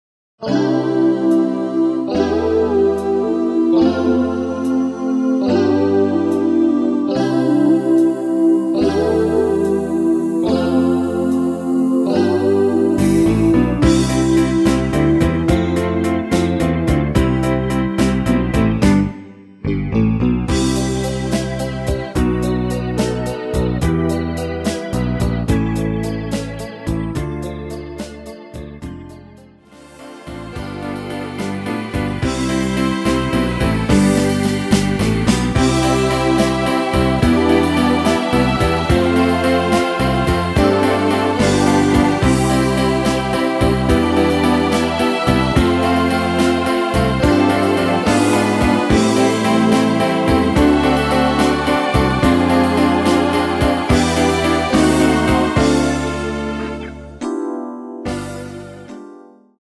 노래방에서 음정올림 내림 누른 숫자와 같습니다.
음정은 반음정씩 변하게 되며 노래방도 마찬가지로 반음정씩 변하게 됩니다.
앞부분30초, 뒷부분30초씩 편집해서 올려 드리고 있습니다.
중간에 음이 끈어지고 다시 나오는 이유는